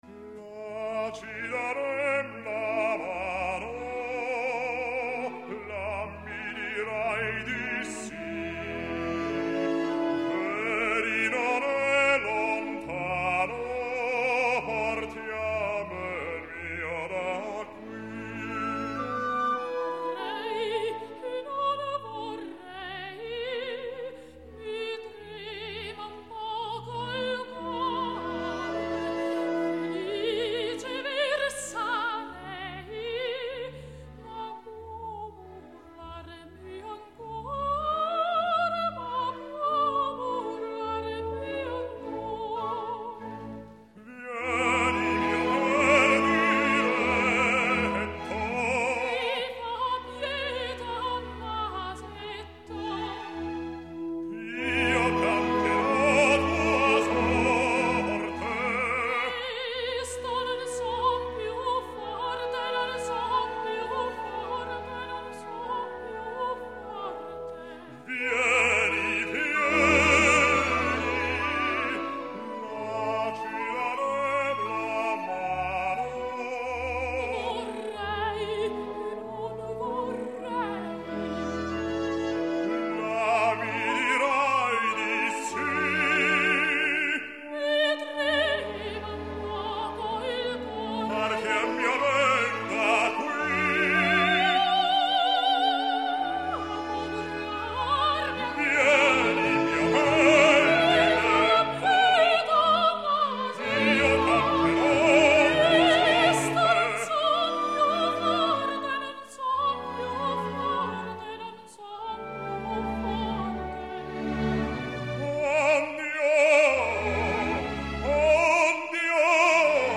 Запись: июнь-июль 1966 года, Лондон.